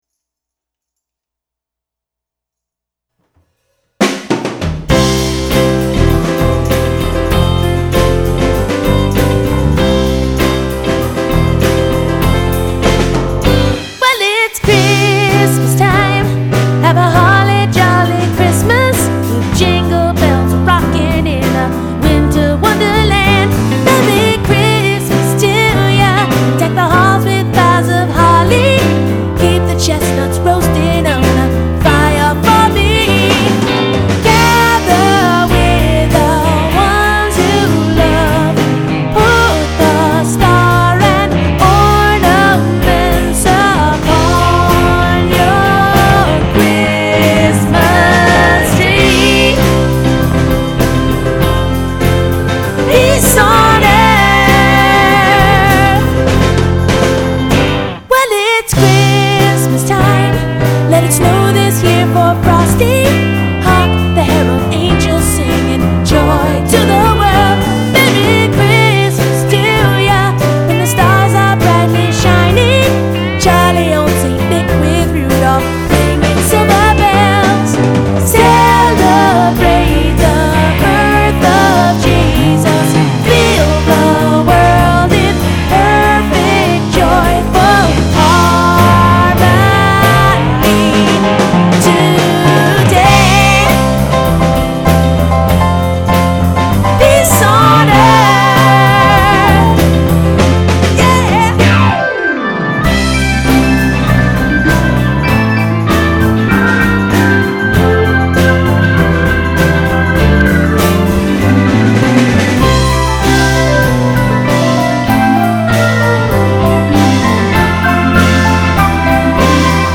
Motown sound - a bit of a departure from anything else
bass
guitar